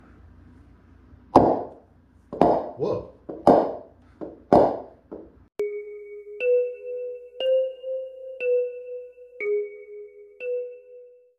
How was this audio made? i dont know whats up with my quality its been really bad